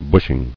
[bush·ing]